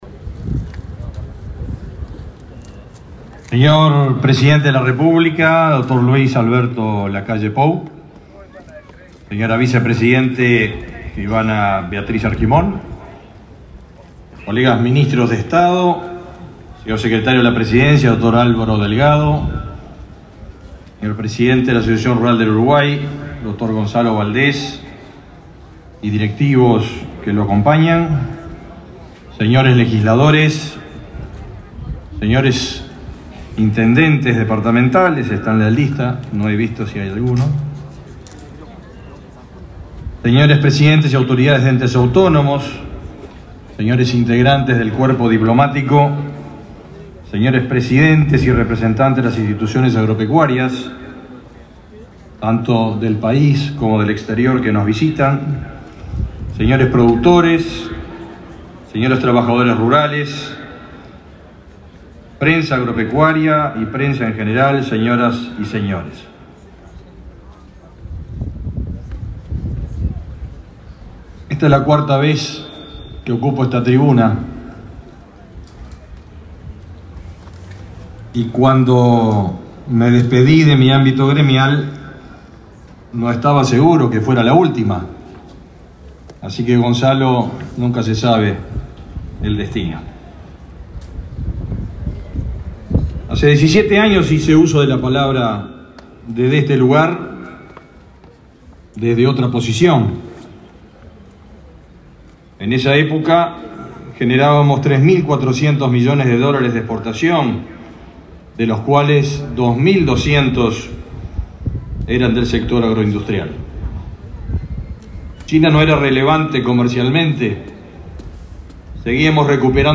El ministro de Ganadería, Fernando Mattos, fue el orador por el Poder Ejecutivo en el acto de clausura de la Expo Prado 2022.